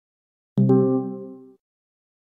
Звук беспроводных наушников Apple AirPods Pro 2 и других в mp3 для монтажа
1. Звук подключения беспроводных наушников Apple AirPods к телефону iPhone
airpods-podkl.mp3